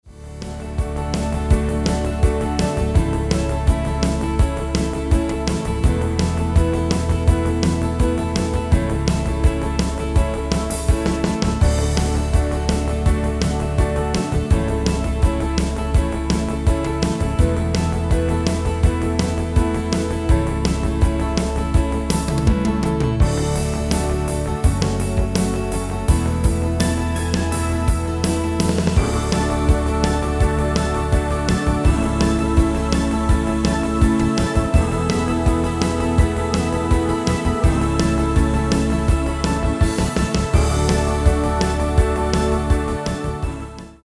Genre: Dutch artists pop / rock
Key: A
Demo's played are recordings from our digital arrangements.